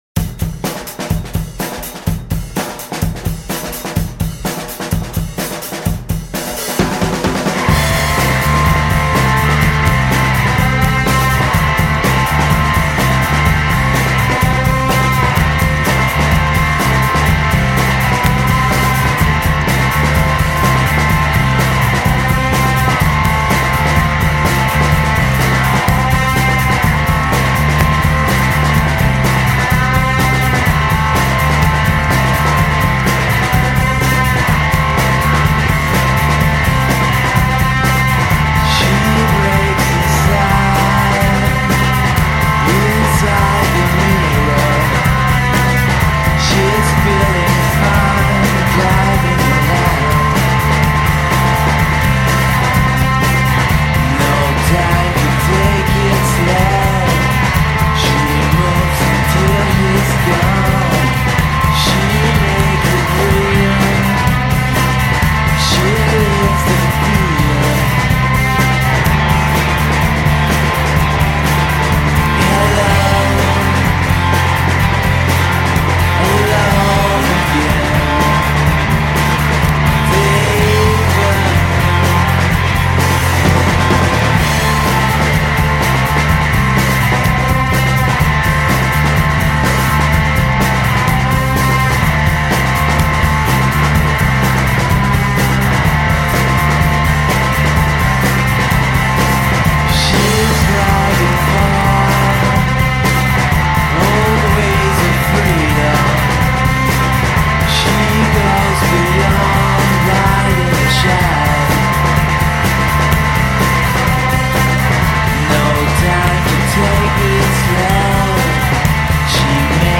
neo-psych/shoe-gaze/indie/experimental band